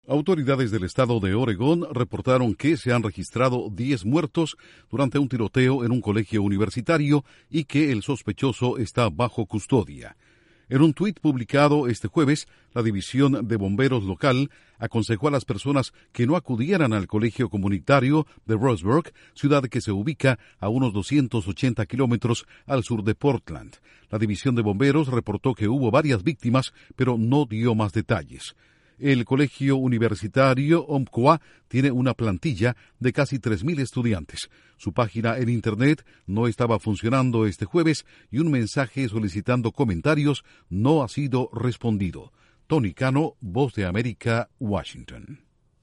VOA: ULTIMA HORA: 10 muertos en Oregon